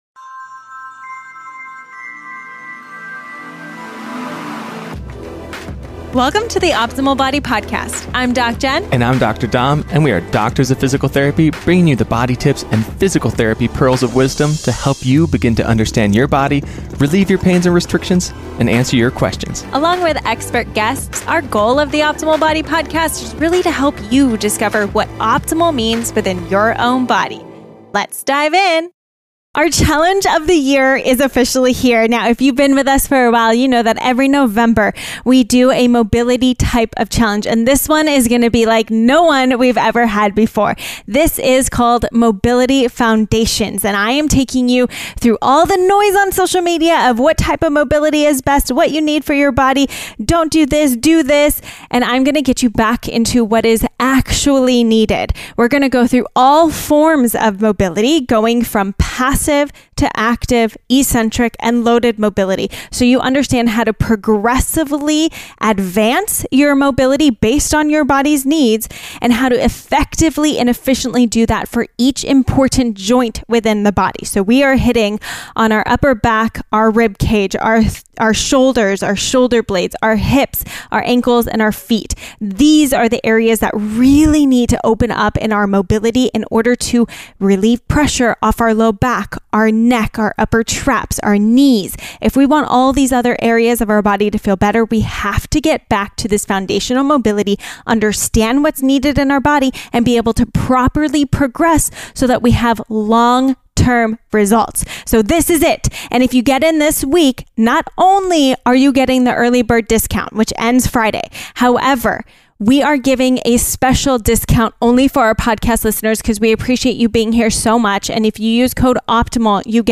Feel like you’ve tried everything, from working out to eating healthy, and still have little to no results? Listen to the interview